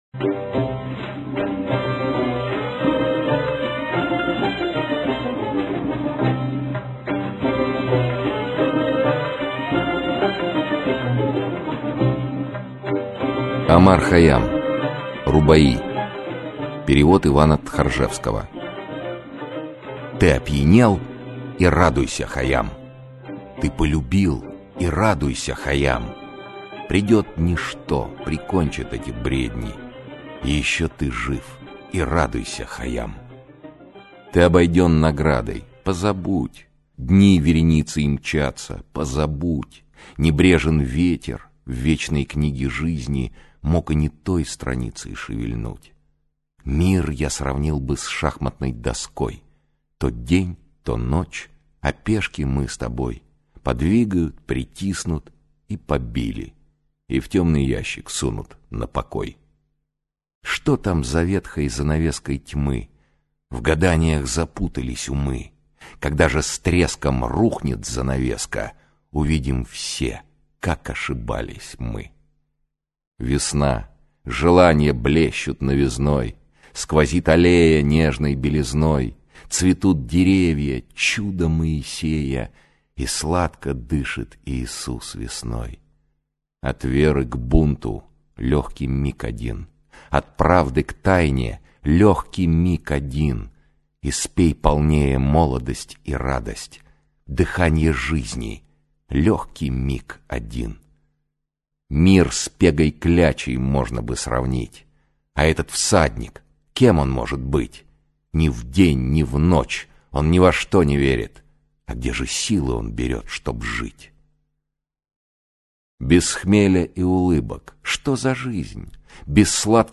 Аудиокнига Рубаи - купить, скачать и слушать онлайн | КнигоПоиск